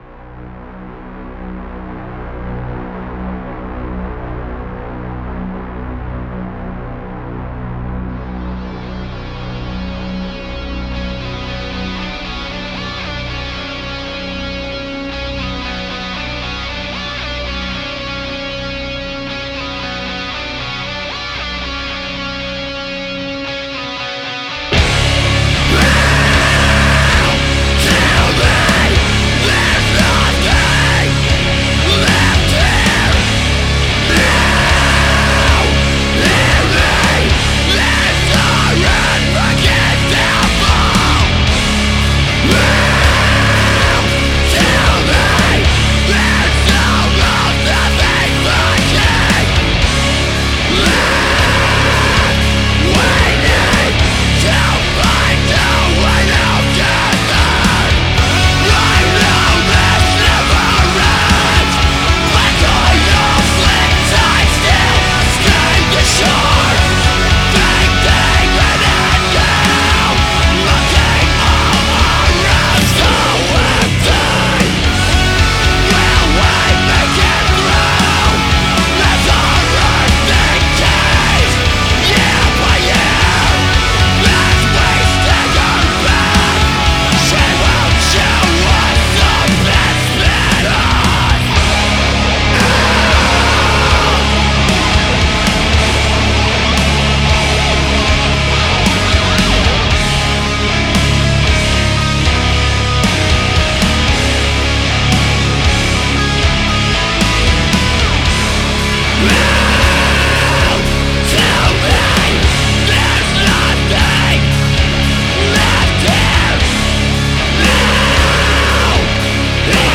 Wirkt etwas Klein du hast arg Komprimiert (das passiert mir auch schon mal bei dieser Mukke :D ) die Mischung öffnet sich dann auch nicht, Transienten gehen flöten es klingt mumpf obere Mitten gehen verloren, die Räume wirken nicht.